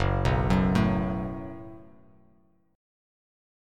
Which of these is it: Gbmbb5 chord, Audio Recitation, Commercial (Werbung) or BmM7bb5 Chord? Gbmbb5 chord